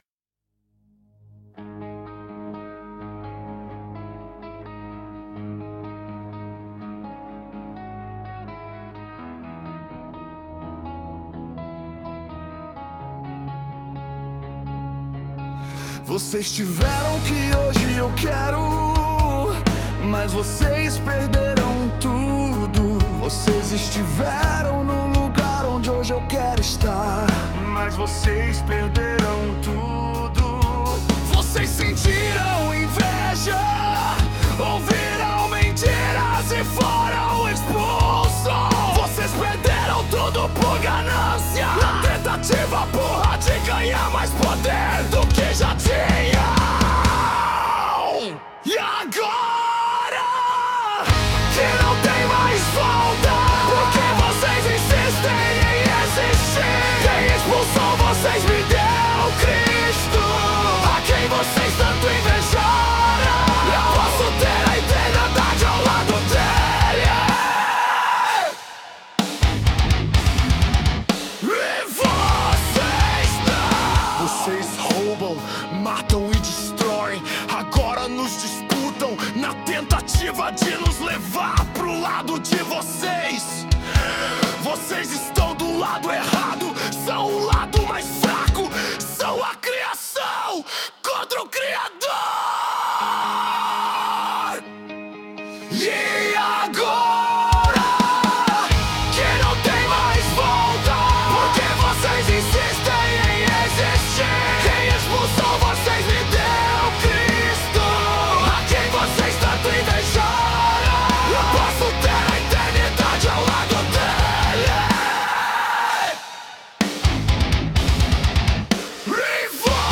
Estilo: Rock/Gospel
Produção: IA